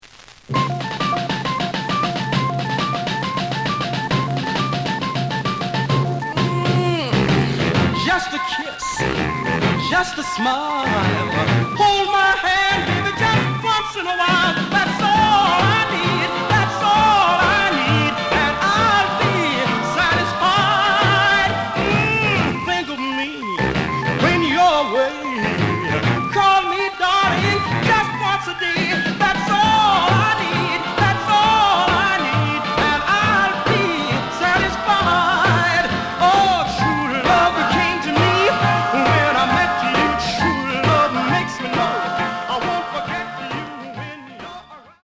The listen sample is recorded from the actual item.
●Genre: Rhythm And Blues / Rock 'n' Roll
Some noise on both sides.)